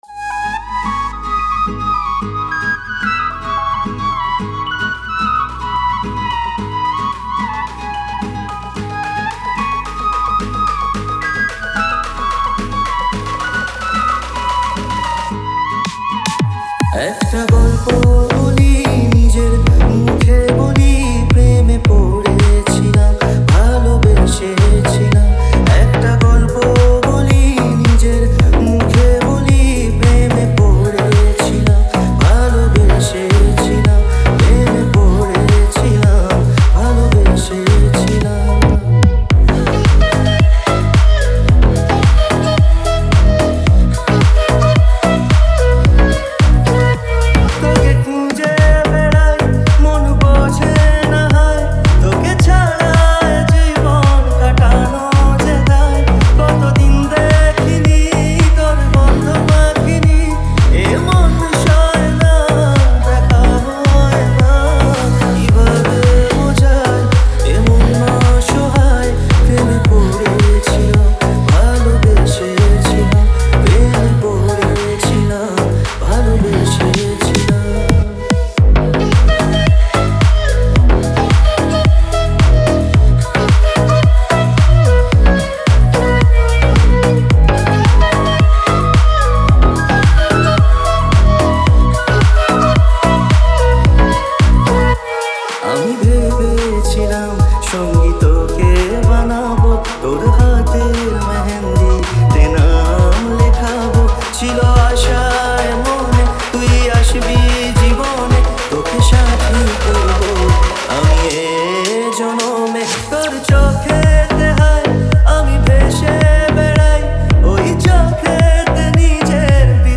Tollywood Single Remixes